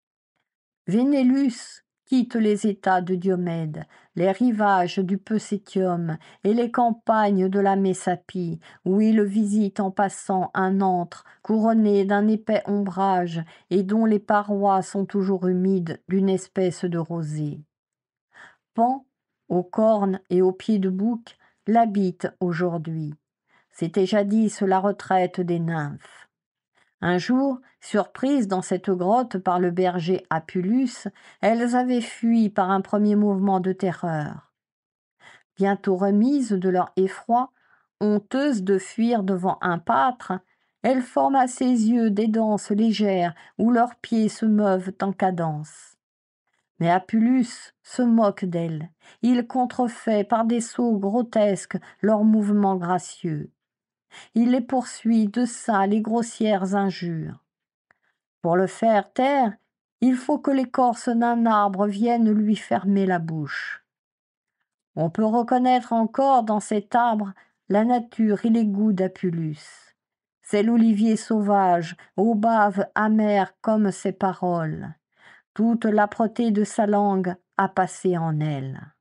Lecture de la métamorphose du pâtre d'Apulie · GPC Groupe 1